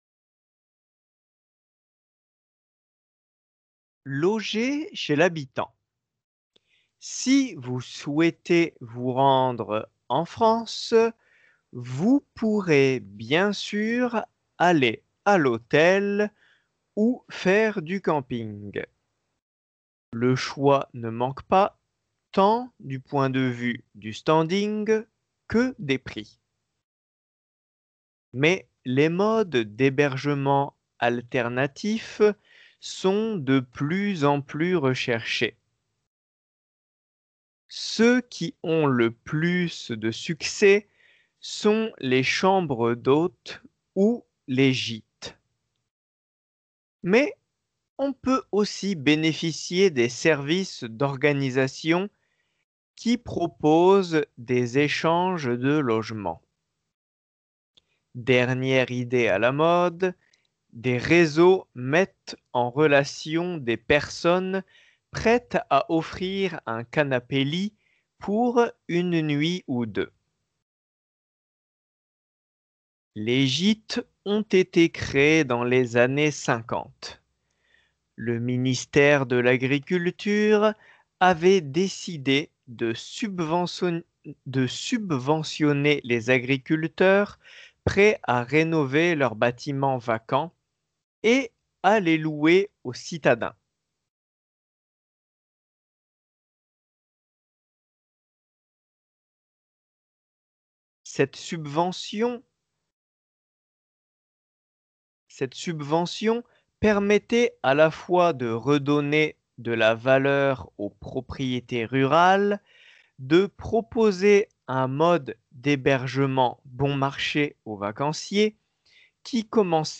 仏検２級用練習音声
文中でsubventionner　が２か所聴きずらくなっております。